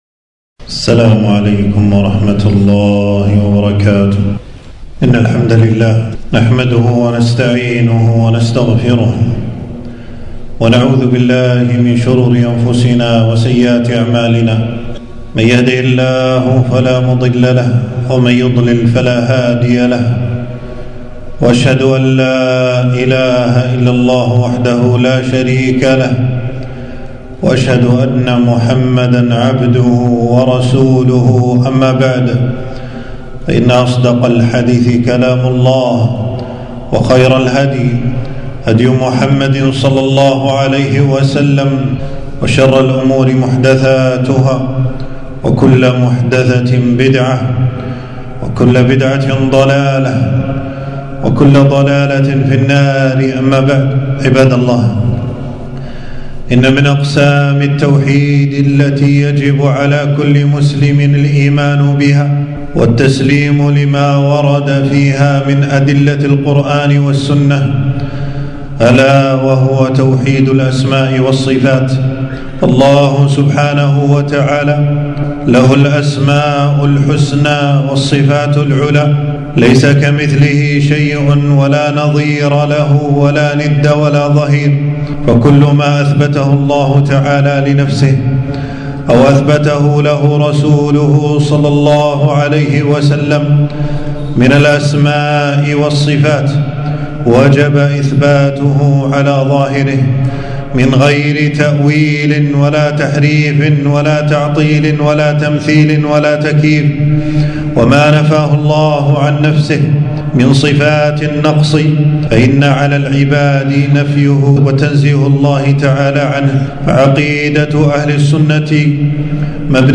خطبة - ( الرحمن على العرش استوى )